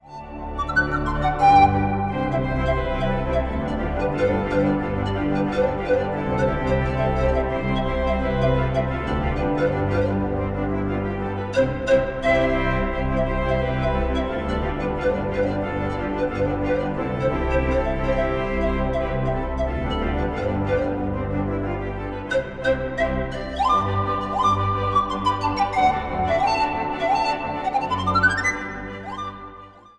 Instrumentaal | Panfluit